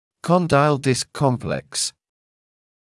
[ˈkɔndaɪl dɪsk ‘kɔmpleks][ˈкондайл диск ‘комплэкс]комплекс диск-мыщелок